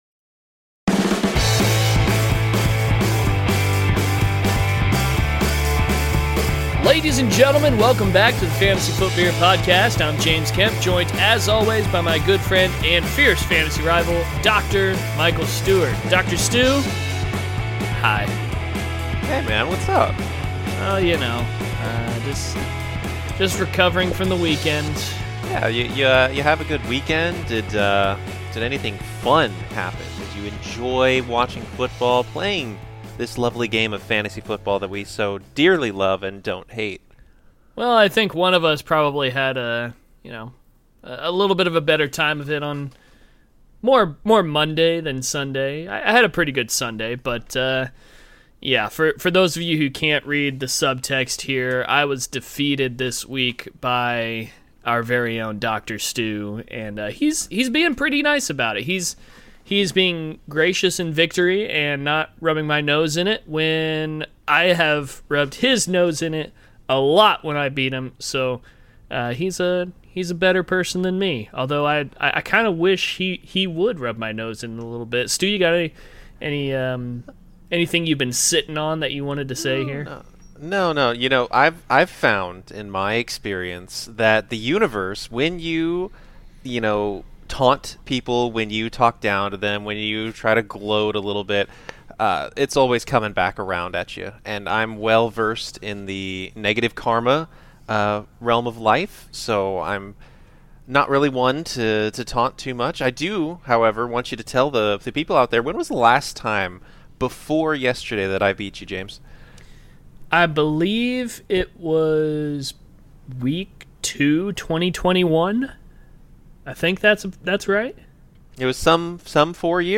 Wherein the guys discuss the fantasy football landscape of week 4, and sound really good doing it.